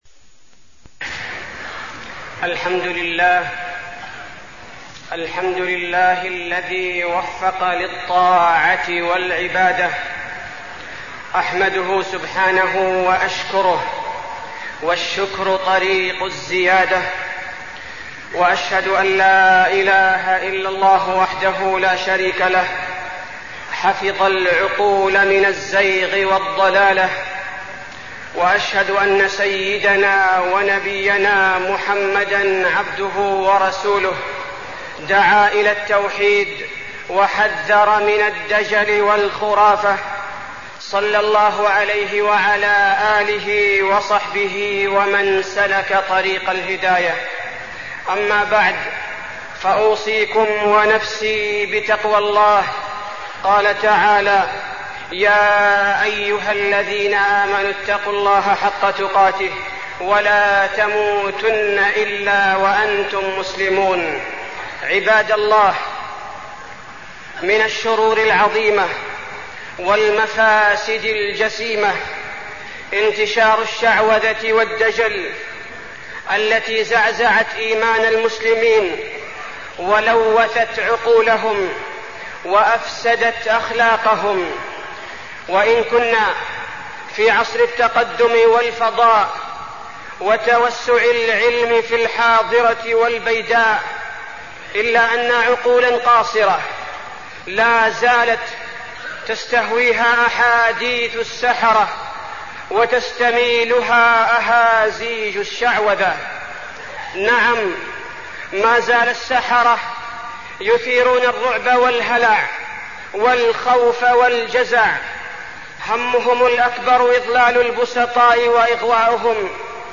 تاريخ النشر ١٤ ذو القعدة ١٤١٥ هـ المكان: المسجد النبوي الشيخ: فضيلة الشيخ عبدالباري الثبيتي فضيلة الشيخ عبدالباري الثبيتي السحر والتمائم